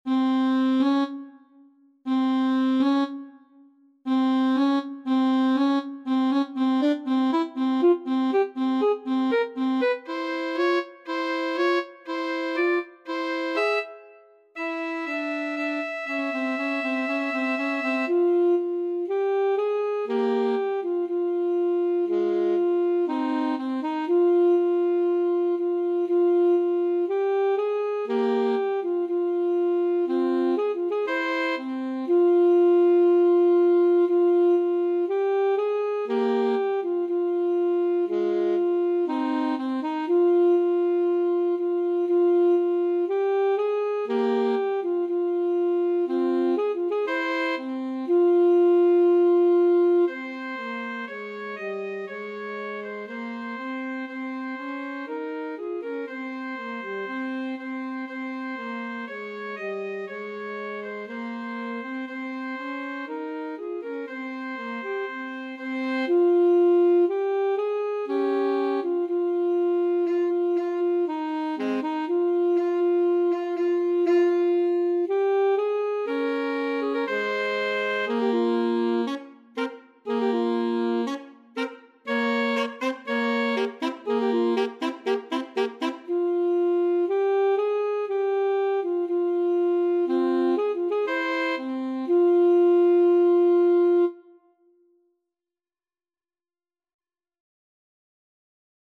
Free Sheet music for Alto Saxophone Duet
F minor (Sounding Pitch) C minor (French Horn in F) (View more F minor Music for Alto Saxophone Duet )
4/4 (View more 4/4 Music)
Allegro con fuoco (View more music marked Allegro)
Alto Saxophone Duet  (View more Easy Alto Saxophone Duet Music)
Classical (View more Classical Alto Saxophone Duet Music)